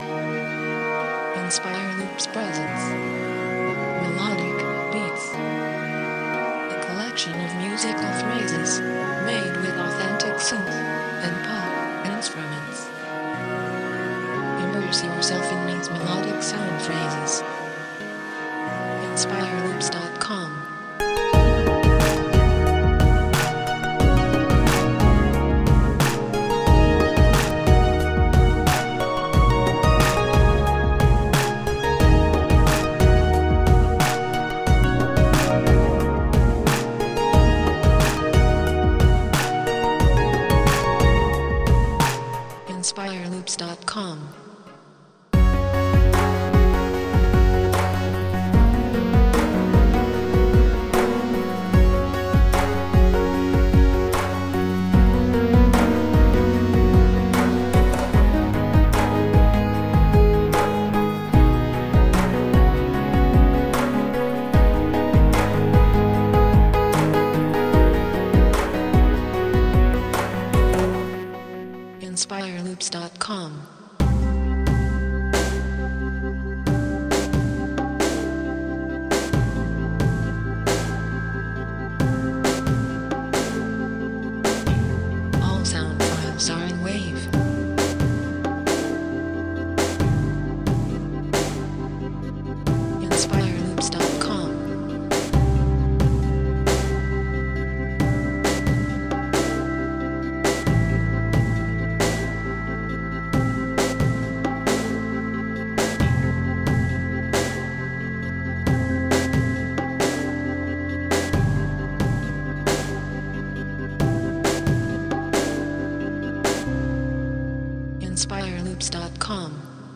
Five songs in construction loop set format
If you are looking for a modern beat with synth elements
BPM - 90Bpm,75 Bpm